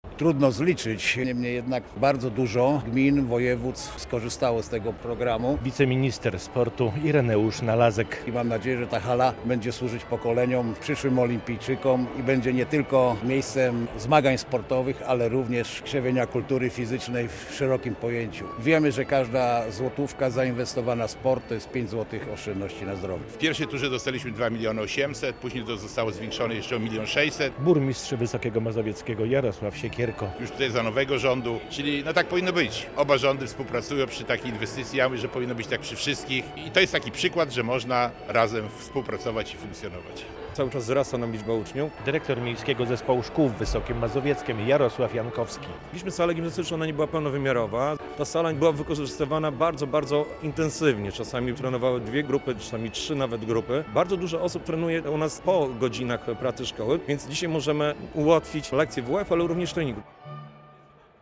Hala Olimpia otwarta. Inwestycja za ponad 6 mln zł w Wysokiem Mazowieckiem - relacja